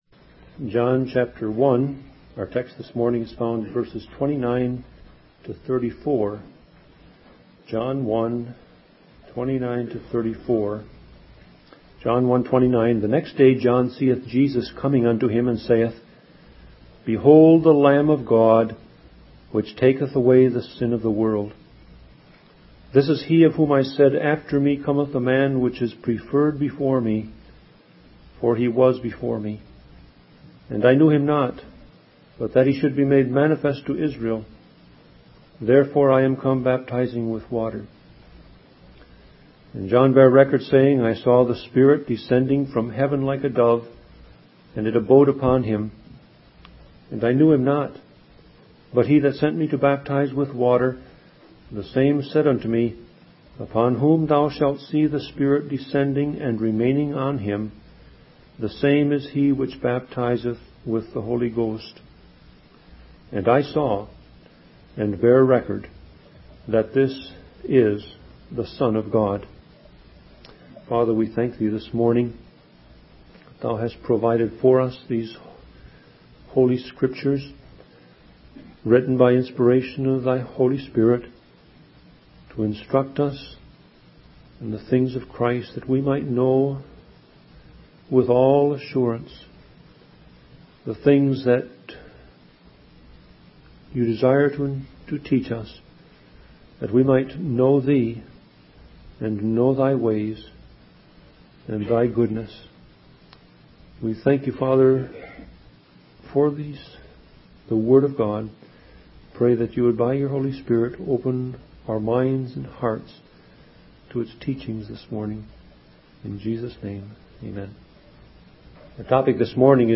Sermon Audio Passage: John 1:29-34 Service Type